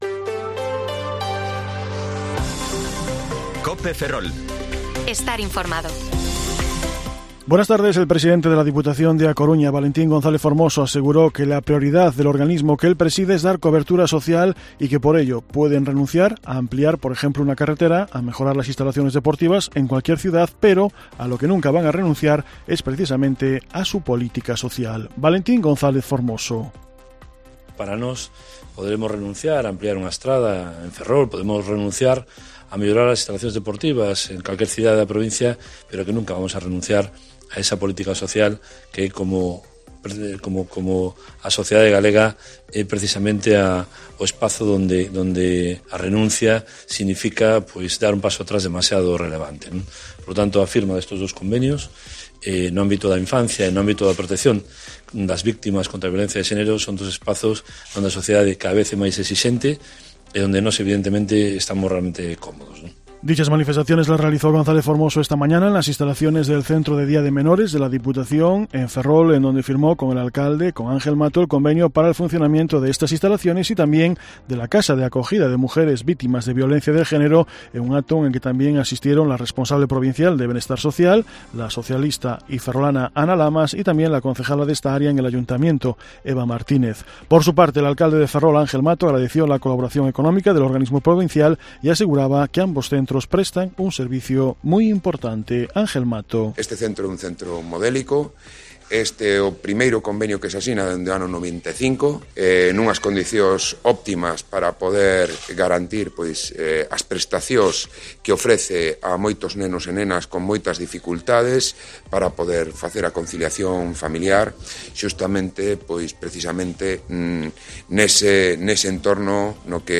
Informativo Mediodía COPE Ferrol 2/5/2023 (De 14,20 a 14,30 horas)